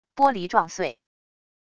玻璃撞碎wav音频